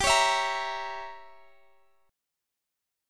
Listen to Gdim7 strummed